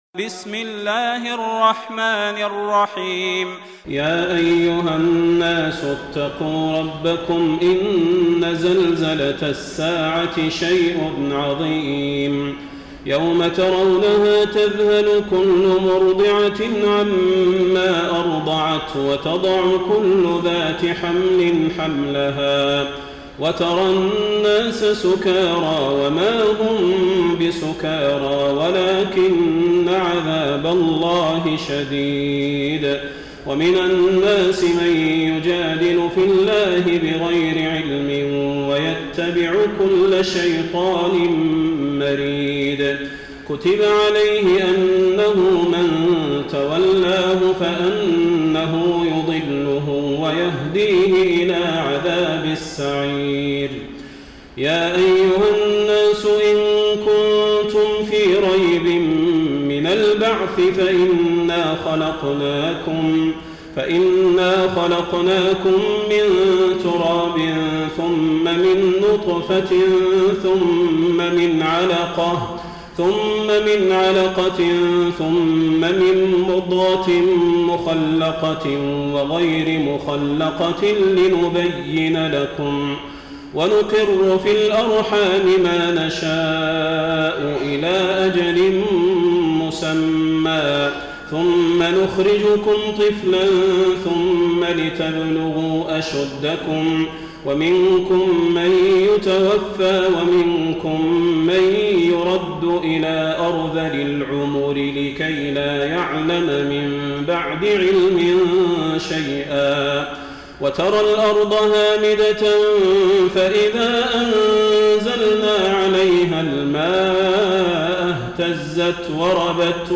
تراويح الليلة السادسة عشر رمضان 1433هـ سورة الحج كاملة Taraweeh 16 st night Ramadan 1433H from Surah Al-Hajj > تراويح الحرم النبوي عام 1433 🕌 > التراويح - تلاوات الحرمين